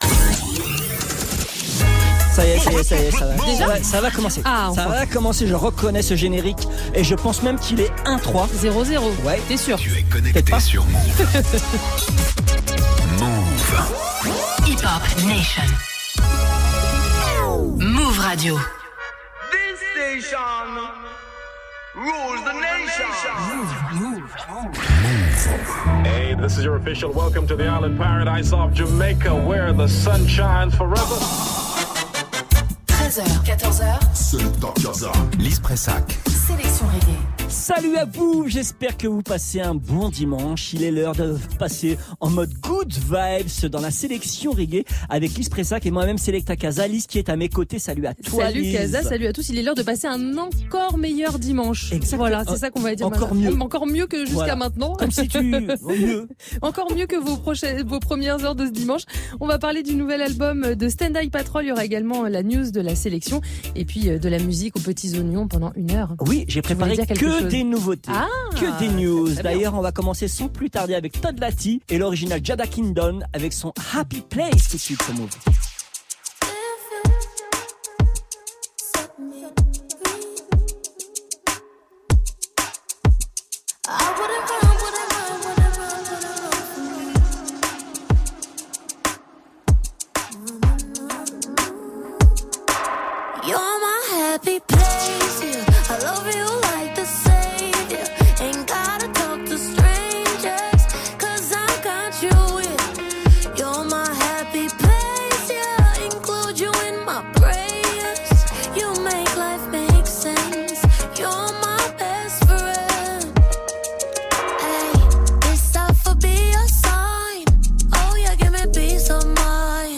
La Sélection Reggae